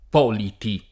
p0liti (per aferesi di Ippoliti) — dubbio l’accento nel caso del lessicografo Adriano P. (1542-1625)